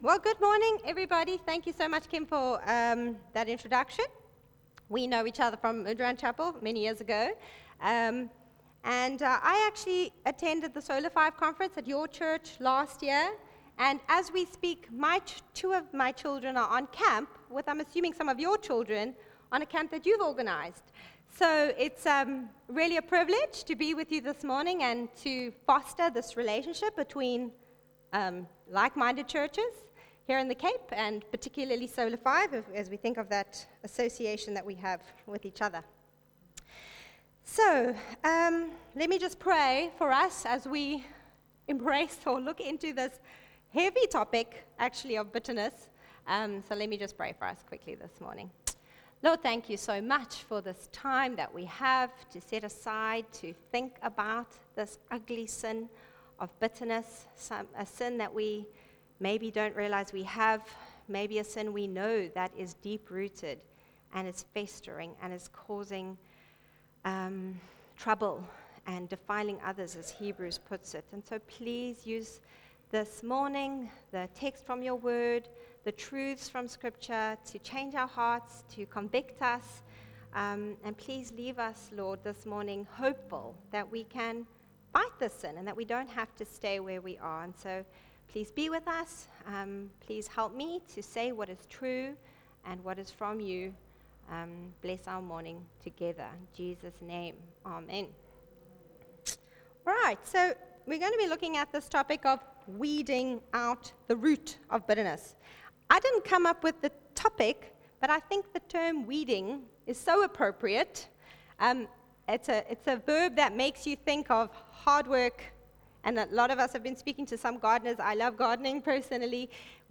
Ladies-Meeting-Nov-2025-Message-Only-1.mp3